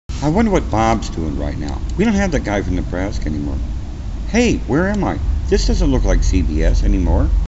His is a simple sweet style which is not fance, just well structured sweet voice.
A Dracula Born Into Another History Another VOICE Too One more From CBS Andy Rooney Tone And Timing Dr. Strangelove Productions I'm Going To Need Females